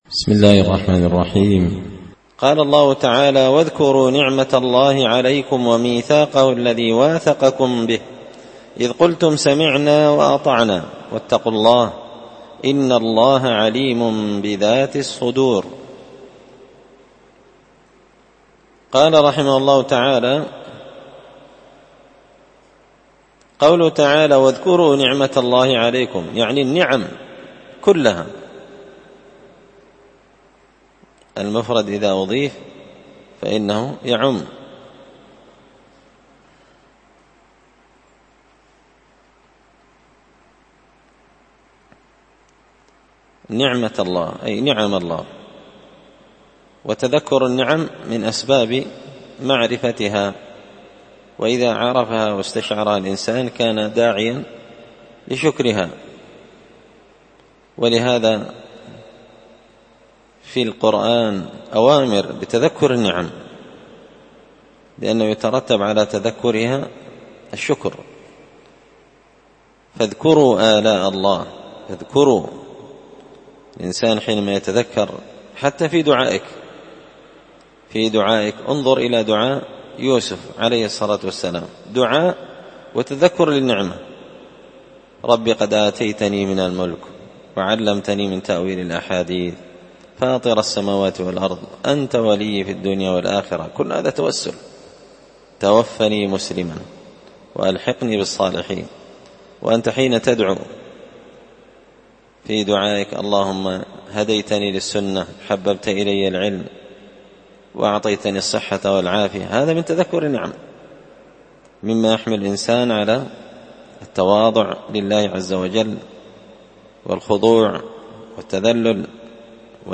مختصر تفسير الإمام البغوي رحمه الله ـ الدرس 239 (سورة المائدة الدرس 12)
دار الحديث بمسجد الفرقان ـ قشن ـ المهرة ـ اليمن